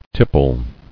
[tip·ple]